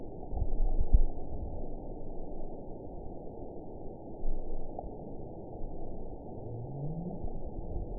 event 921758 date 12/18/24 time 22:21:54 GMT (6 months ago) score 9.65 location TSS-AB03 detected by nrw target species NRW annotations +NRW Spectrogram: Frequency (kHz) vs. Time (s) audio not available .wav